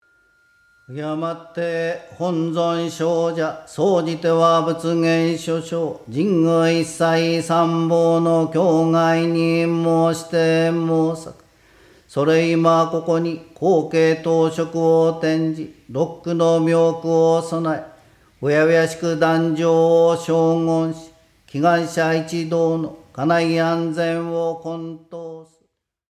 こちらのオーディオブックでは本堂完全LIVE録音の